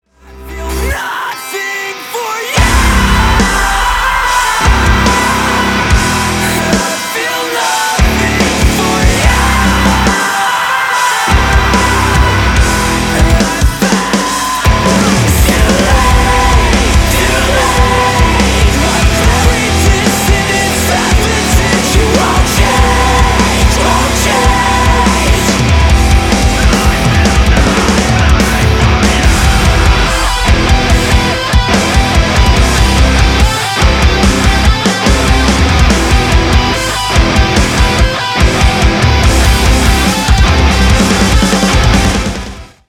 Рок рингтоны
мужской вокал грустные
alternative rock Драйвовые